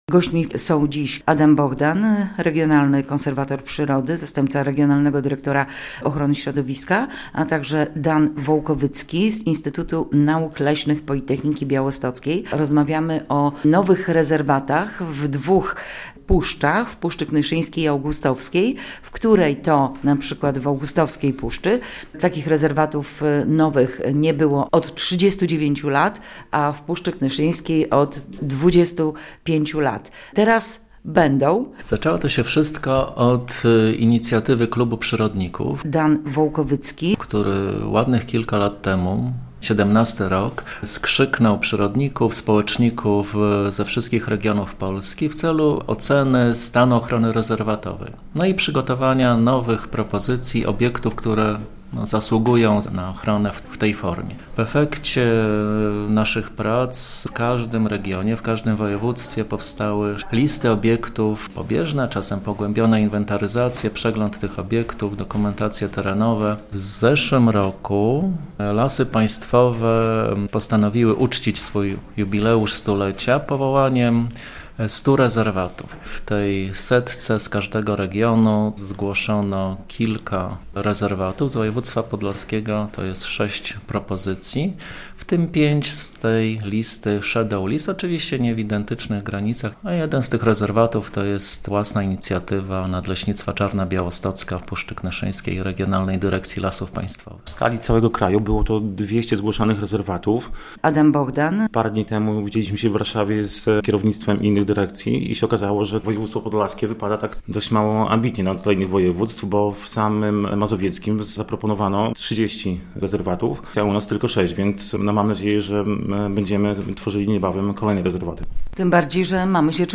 rozmawia z Gośćmi Polskiego Radia Białystok